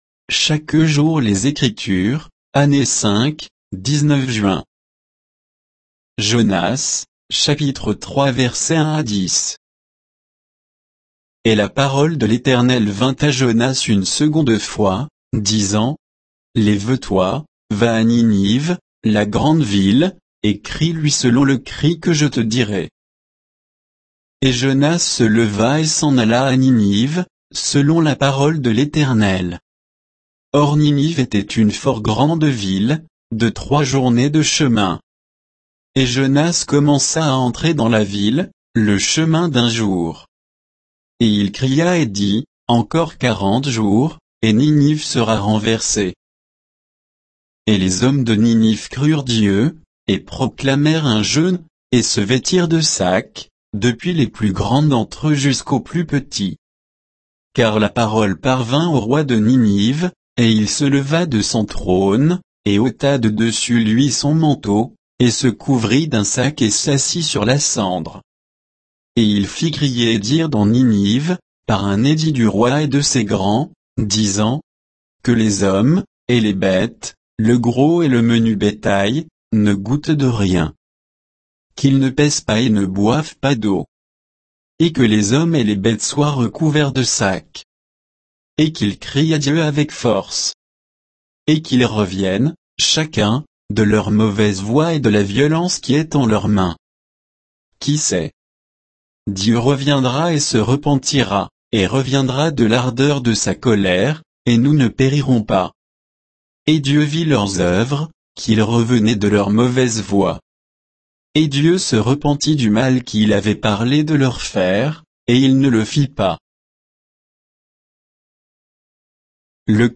Méditation quoditienne de Chaque jour les Écritures sur Jonas 3